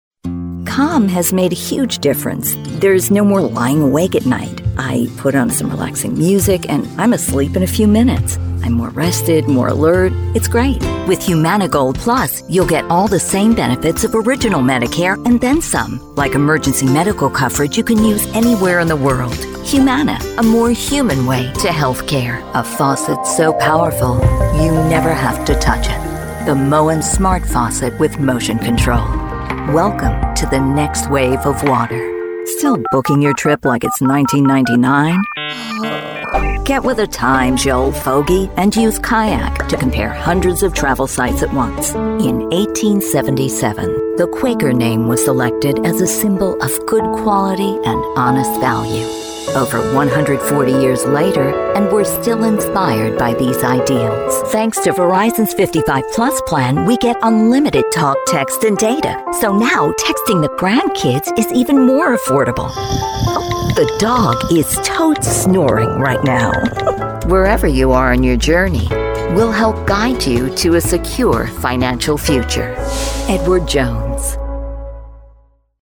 Category: Female